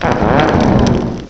Add all new cries
cry_not_pignite.aif